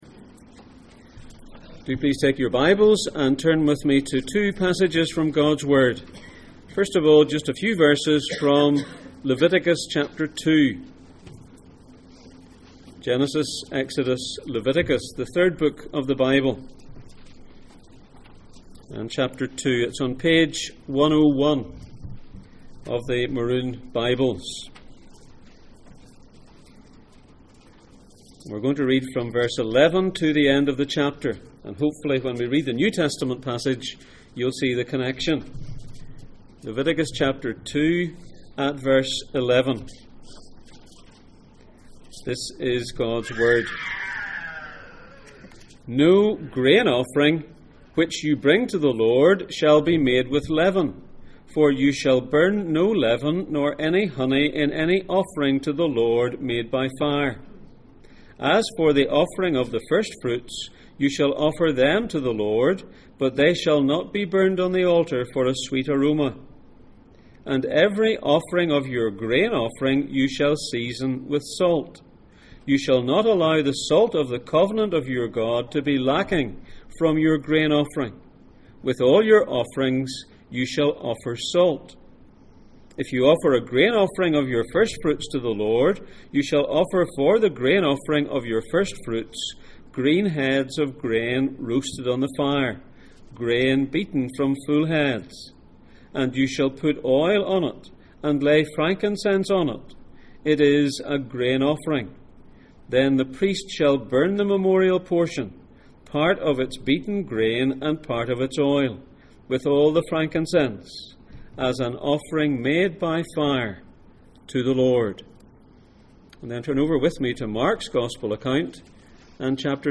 Jesus in Mark Passage: Mark 9:42-50, Leviticus 2:11-16, Colossians 3:5-8 Service Type: Sunday Morning